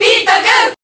File usage The following 2 pages use this file: List of crowd cheers (SSBB)/Japanese Pit (SSBB) Transcode status Update transcode status No transcoding required.
Pit_Cheer_Japanese_SSBB.ogg